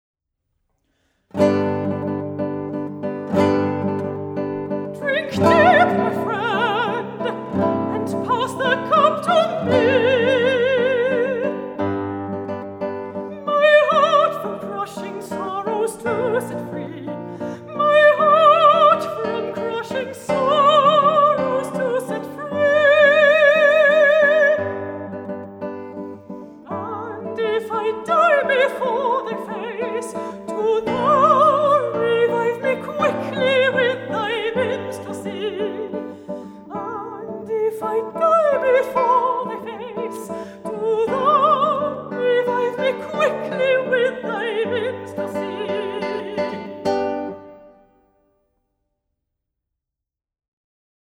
guitar
mezzo-soprano